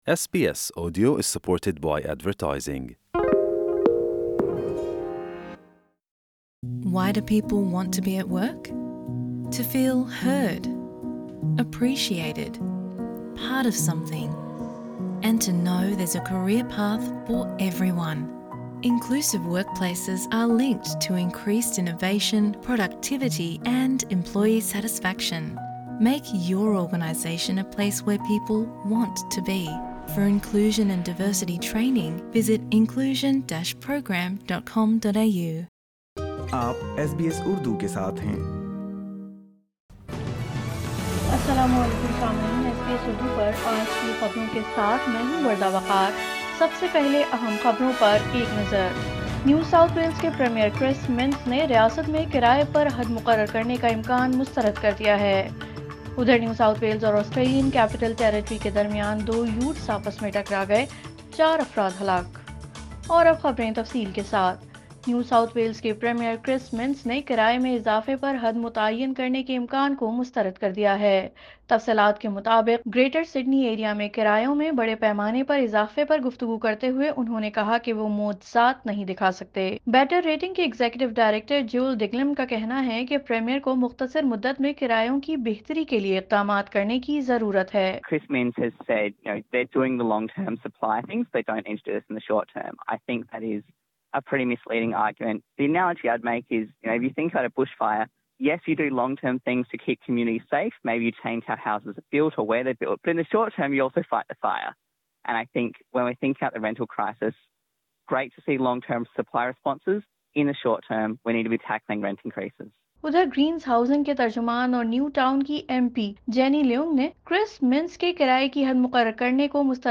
اردو خبریں جمعہ 07 اپریل 2023 : کرایوں میں اضافے پر حد لگانے کو نیو ساوتھ ویلز کے پرئیمئر نے مسترد کردیا